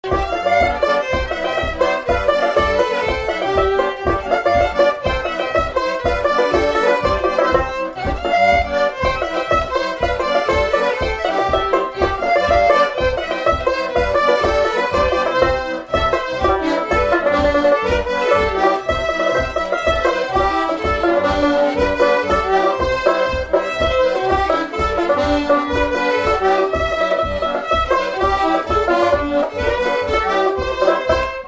Résumé Instrumental
danse : séga
Pièce musicale inédite